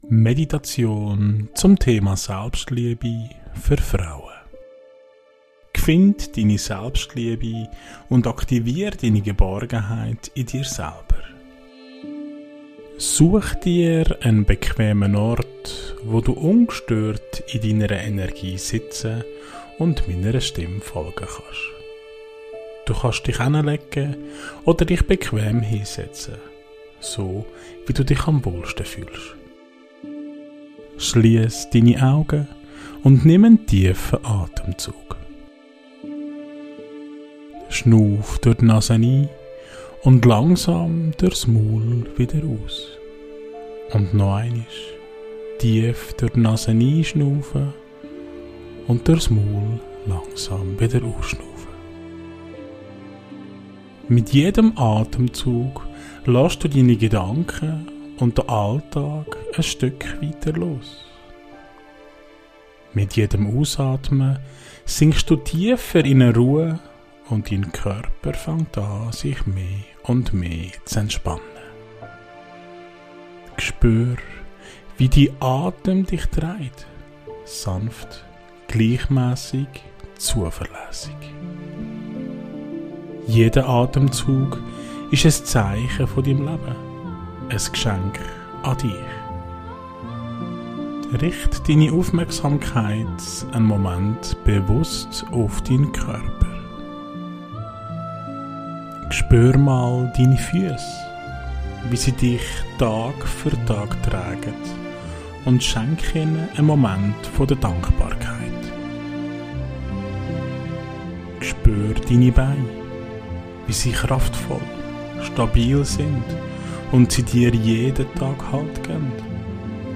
Beschreibung vor 3 Monaten Meditation für Frauen – Finde deine Selbstliebe und aktiviere die Geborgenheit in dir selbst Diese geführte Meditation lädt dich ein, die Verbindung zu dir selbst zu vertiefen und Selbstliebe, Geborgenheit und innere Sicherheit in dir zu aktivieren. Mit sanfter Atmung und bewusster Körperwahrnehmung kommst du Schritt für Schritt zur Ruhe.